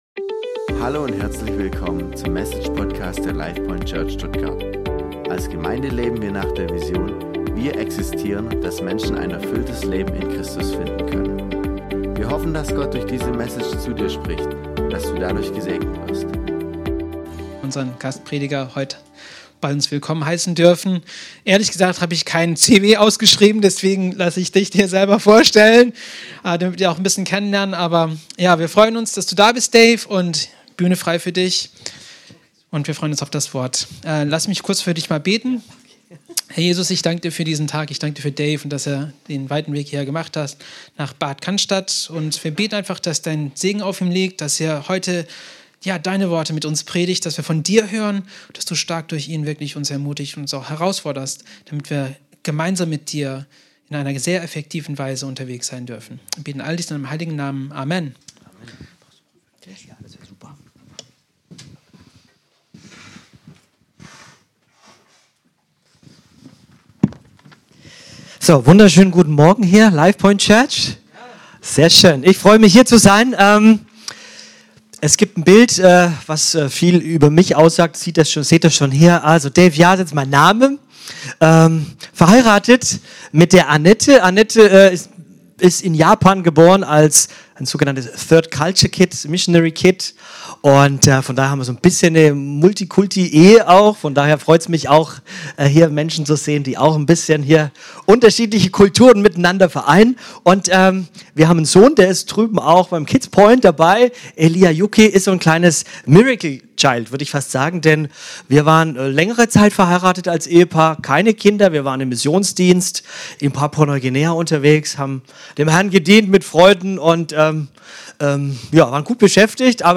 Die drei Tests – Bedürftigkeit, Integrität und Prioritäten – zeigen, wie wichtig es ist, auf Gottes Wort zu vertrauen, unsere Stärken nicht für egoistische Zwecke zu nutzen und unser Herz vor Götzen wie Macht oder Reichtum zu bewahren. Die Predigt ermutigt, in Versuchungen standhaft zu bleiben und den Glauben zu vertiefen, indem wir uns auf Gott und seine Führung verlassen.